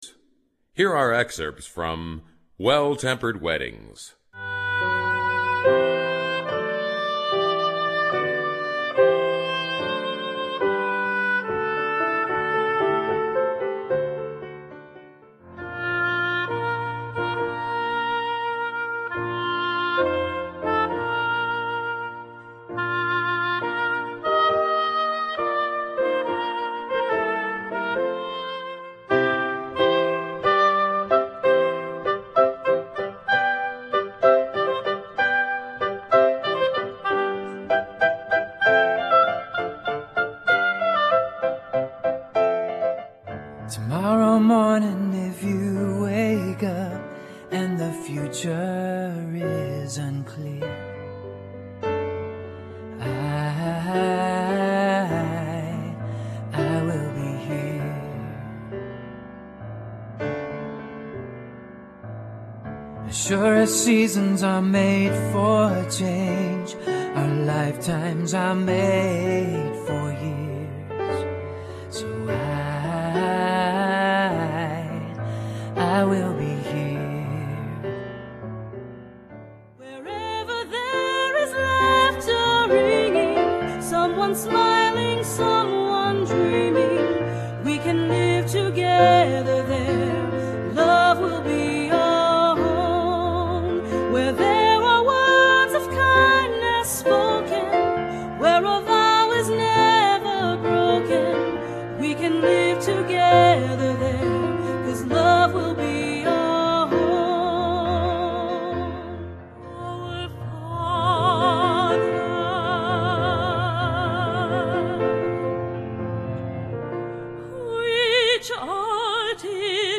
Voicing: Piano/Vocal